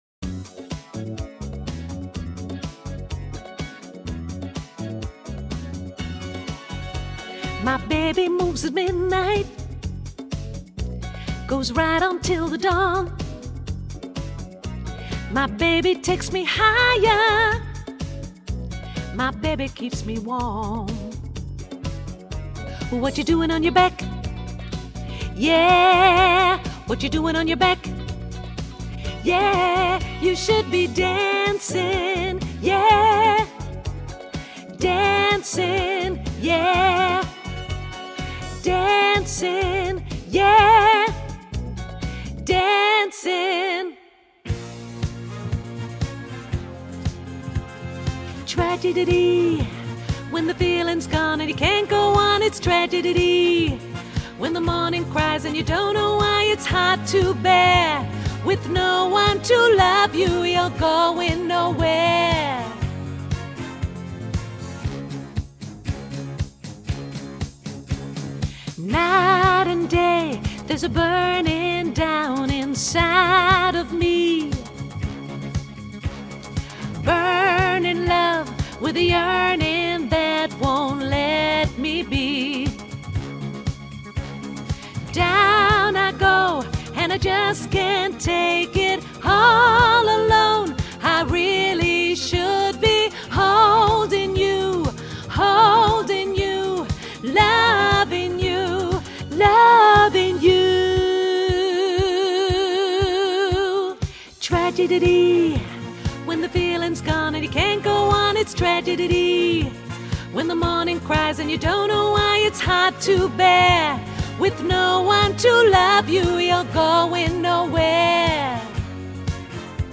alt